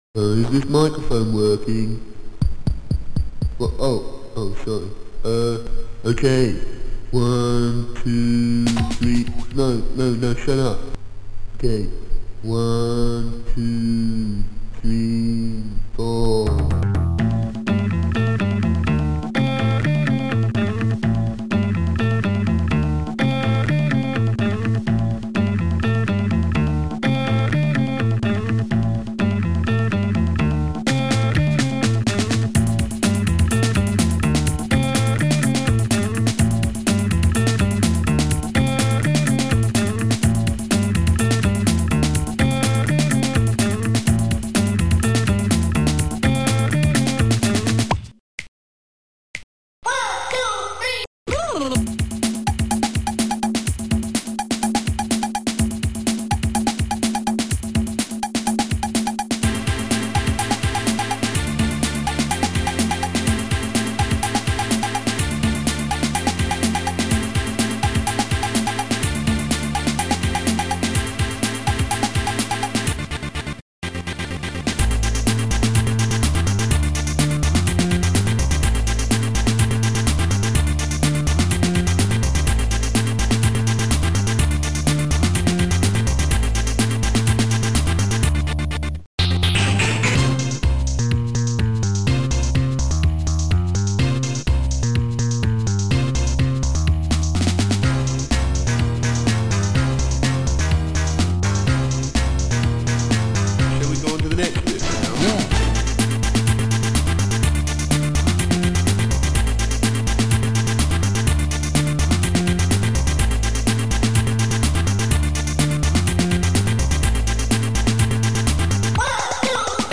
Sound Style: Guitar / Speech